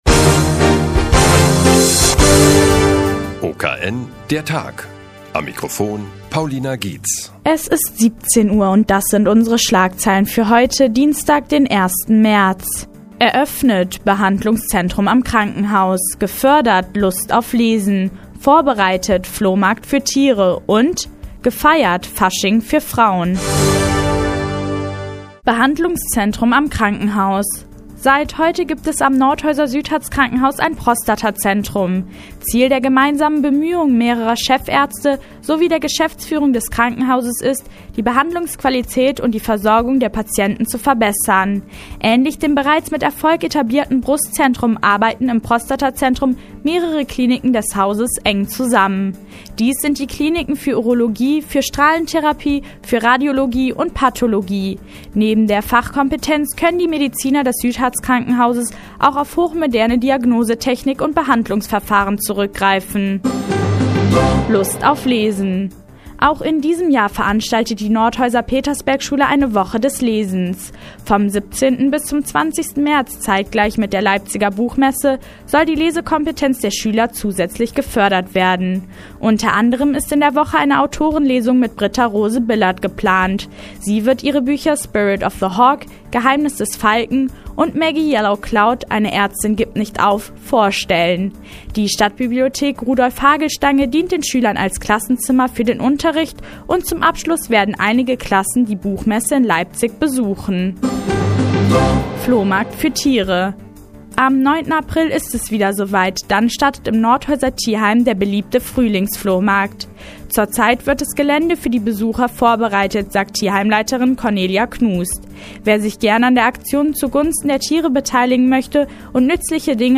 Die tägliche Nachrichtensendung des OKN ist nun auch in der nnz zu hören.